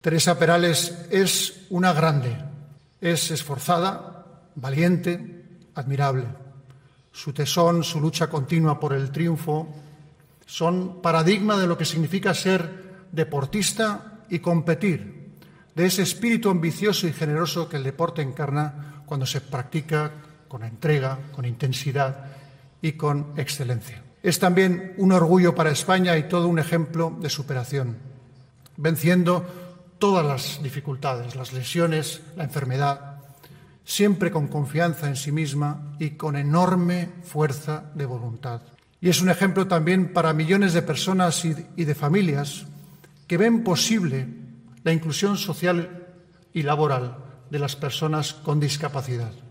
Fue el 22 de octubre en el Teatro Campoamor de Oviedo, en la ceremonia en la que la laureada nadadora paralímpica recogía el Premio Princesa de Asturias de los Deportes 2021.